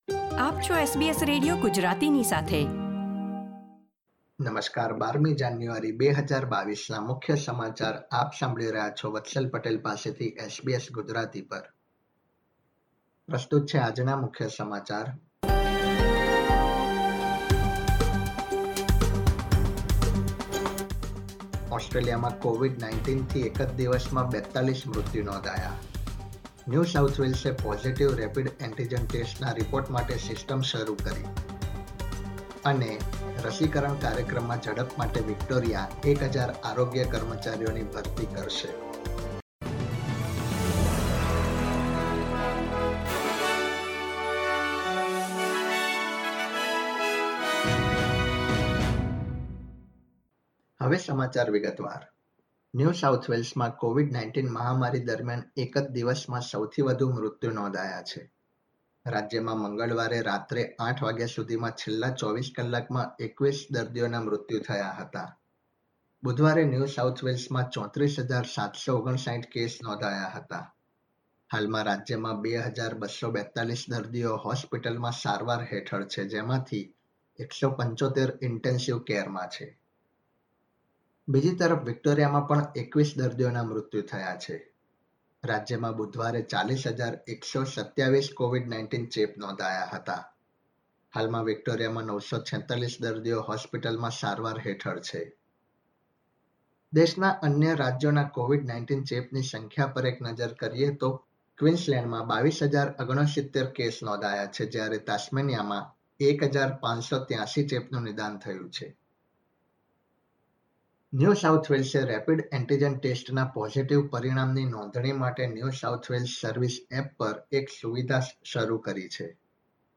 SBS Gujarati News Bulletin 12 January 2022